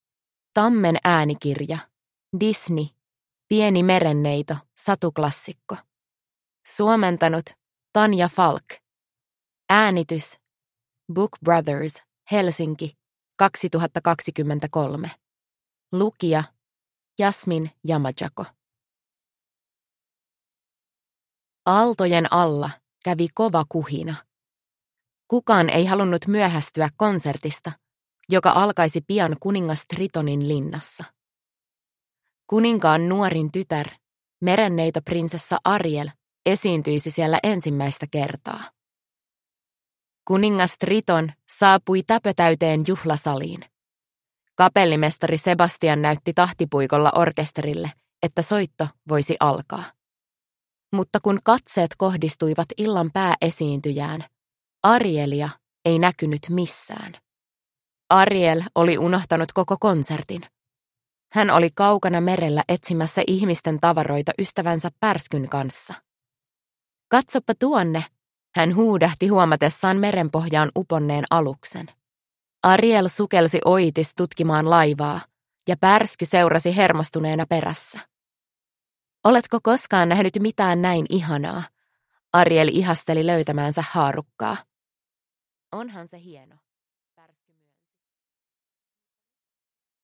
Pieni merenneito. Satuklassikot – Ljudbok – Laddas ner